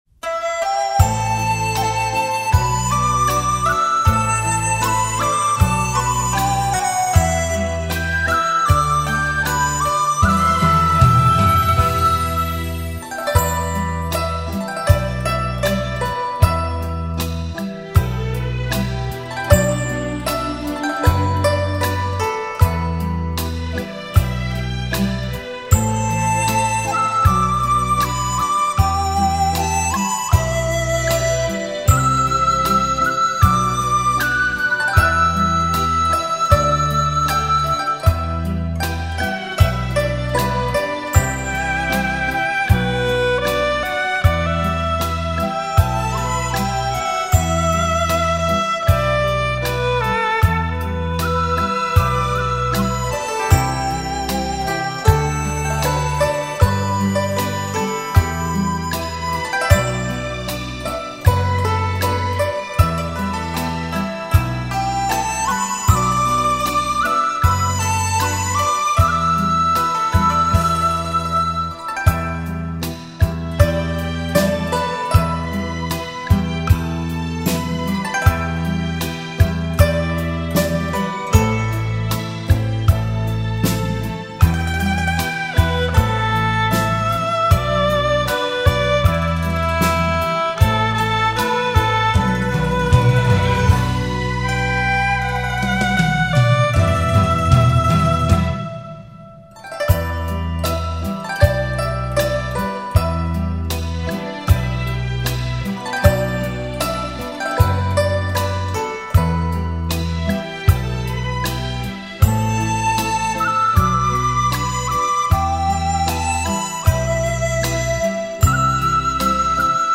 封面秀丽的景致，爽心的民族音乐，泡上一壶清茶，思忆着往昔旧时的趣事、轶事，
熟悉的旋律，动听的音符，